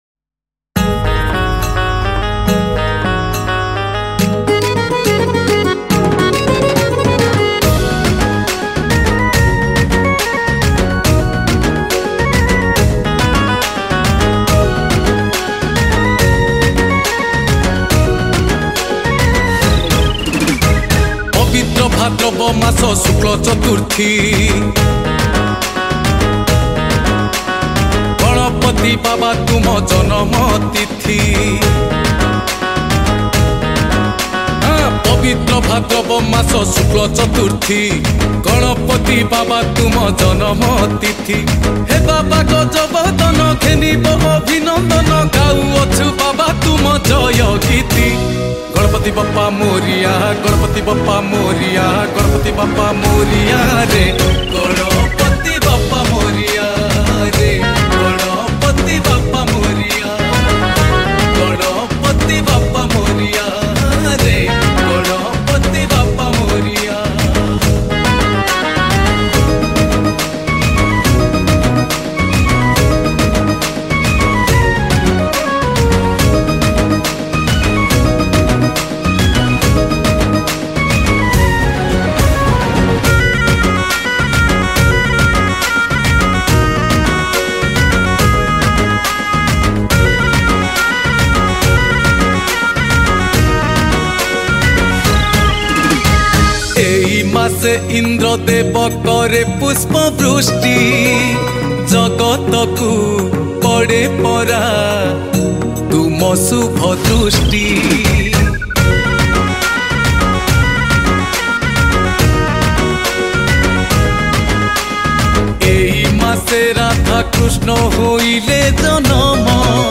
Category: Ganesh Puja Special Odia Songs